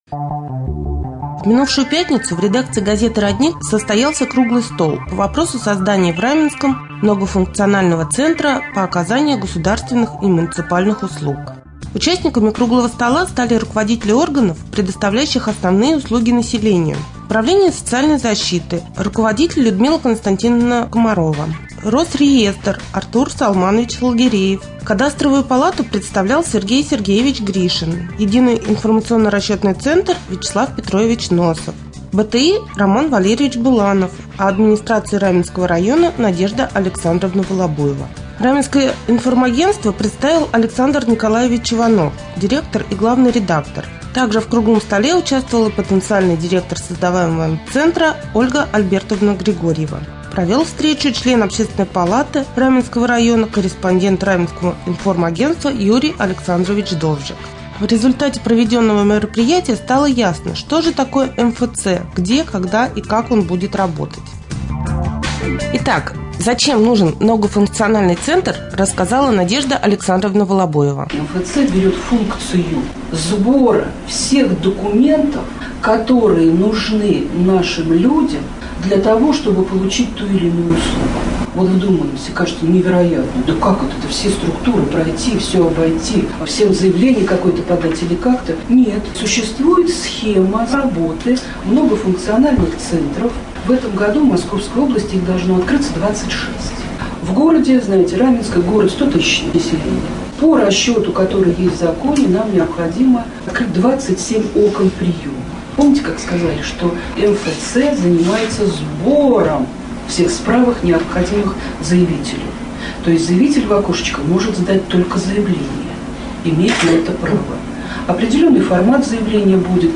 3.Рубрика «Специальный репортаж». В Раменском информагентстве прошел круглый стол на тему создания многофункционального центра.